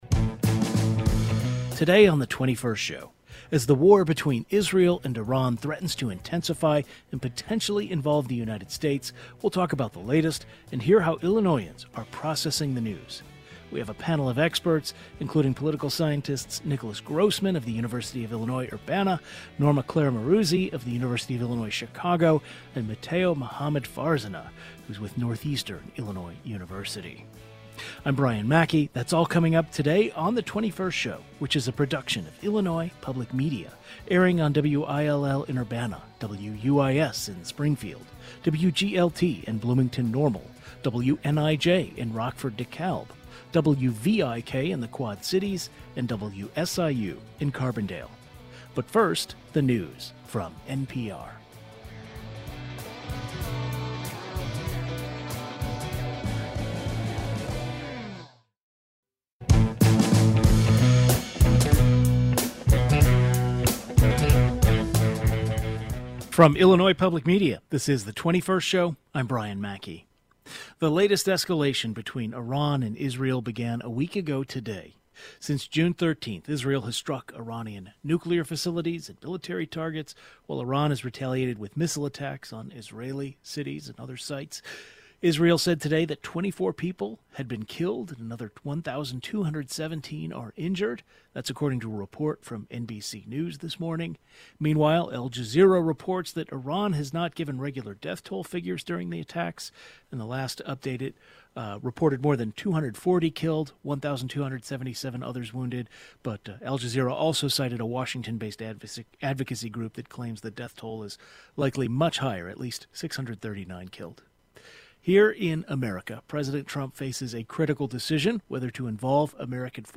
A panel of experts in Middle East affairs, history, and geopolitics join the conversation.